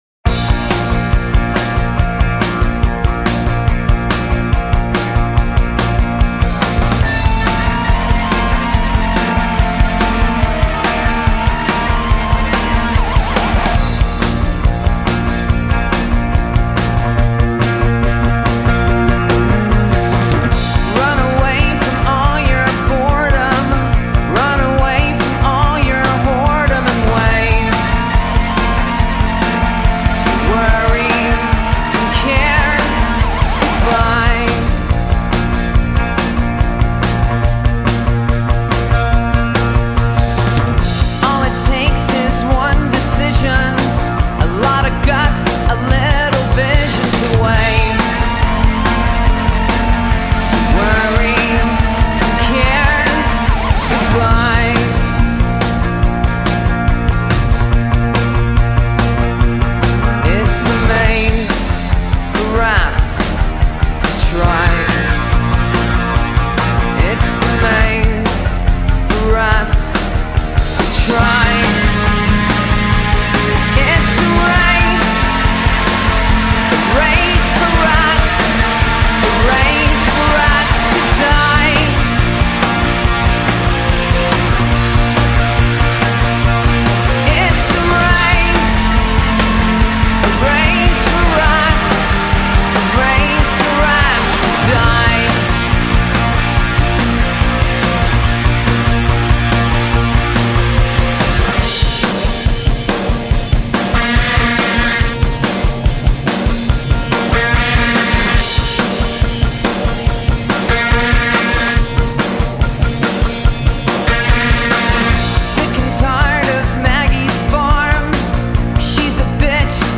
Альтернативный рок Инди рок Рок